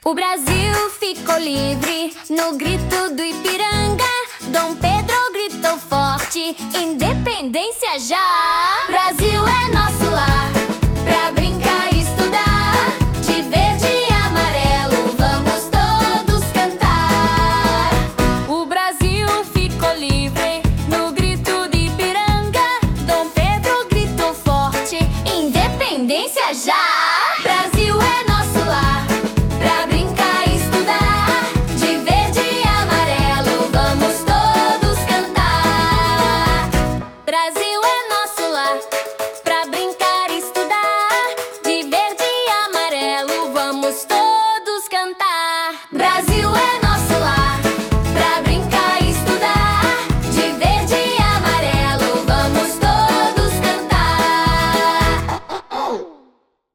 MÚSICA INFANTIL SOBRE A INDEPENDÊNCIA DO BRASIL: APRENDIZADO LÚDICO E DIVERTIDO PARA A EDUCAÇÃO INFANTIL
A canção “O Brasil ficou livre” foi criada com linguagem simples e infantil, facilitando a compreensão das crianças. O refrão alegre e fácil de memorizar promove o senso de união e identidade, além de incentivar a participação ativa em cantos e brincadeiras.